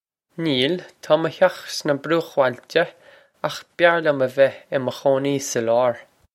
Pronunciation for how to say
Neel. Taw muh hyokh sna brukh-wohl-cha, okh byar lyum veh ih muh khoh-nee sa lawr.